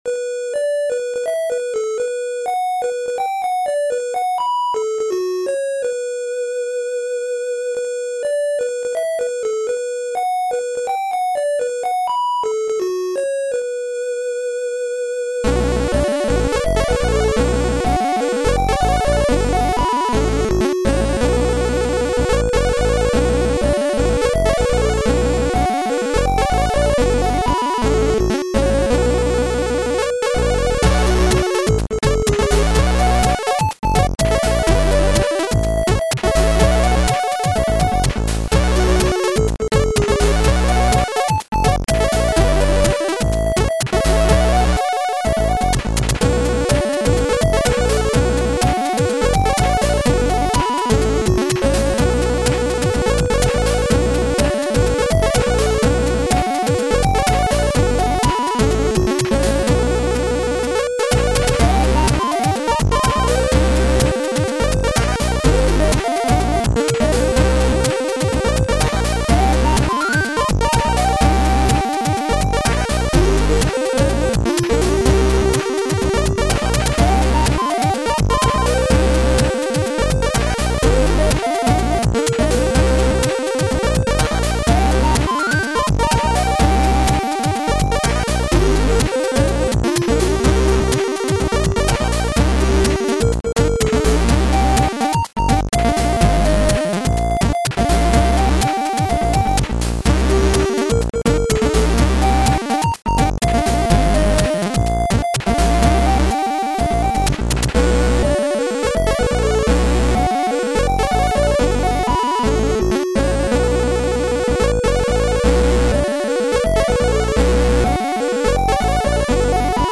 Chip Music Pack